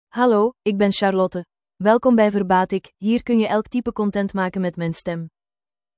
Charlotte is a female AI voice for Dutch (Belgium).
Voice: CharlotteGender: FemaleLanguage: Dutch (Belgium)ID: charlotte-nl-be
Voice sample
Listen to Charlotte's female Dutch voice.
Charlotte delivers clear pronunciation with authentic Belgium Dutch intonation, making your content sound professionally produced.